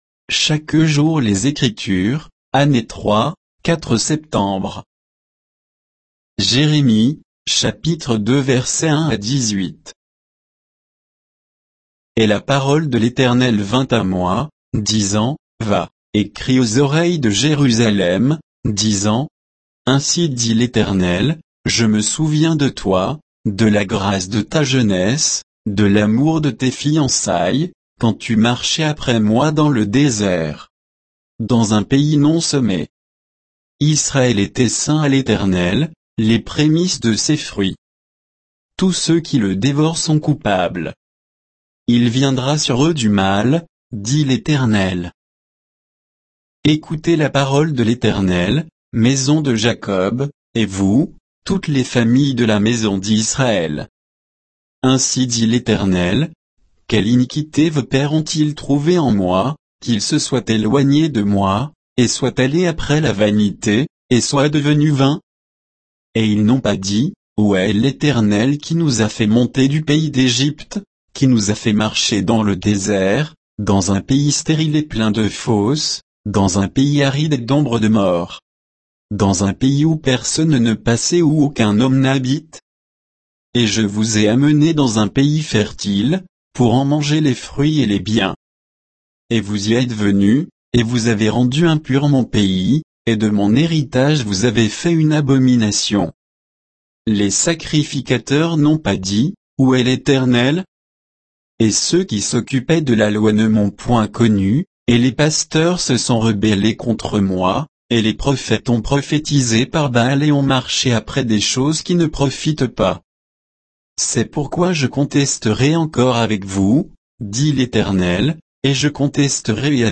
Méditation quoditienne de Chaque jour les Écritures sur Jérémie 2